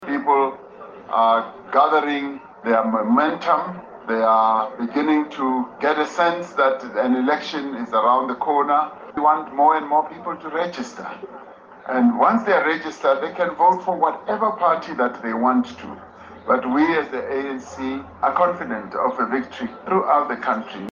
# President Cyril Ramaphosa has urged all eligible voters to actively participate in shaping South Africa’s future by registering to vote in the Independent Electoral Commission’s registration drive today. Speaking from an IEC registration center in Meadowlands, Soweto, Ramaphosa underscored that becoming a registered voter is the first crucial step for South Africans to take control of their future. He emphasises the significance of using the freedom to make their mark, and encourages eligible citizens to exercise their right to contribute to the democratic process.